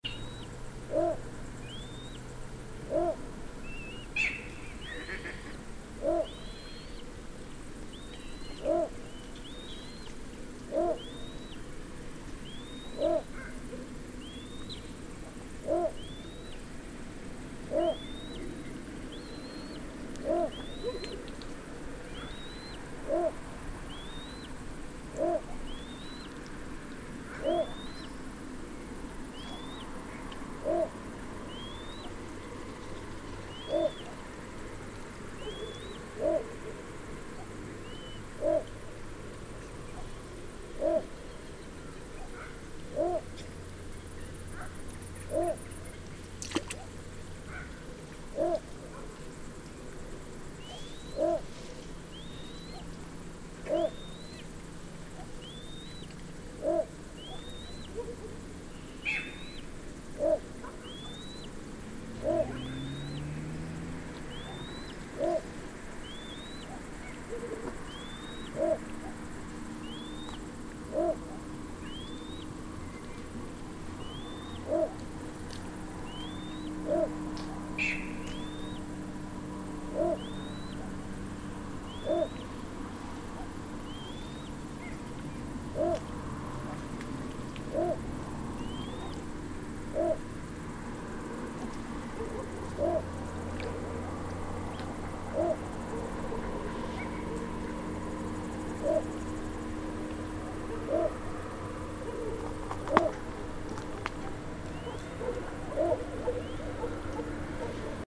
Tarabusino-Ixobrychus-minutus.mp3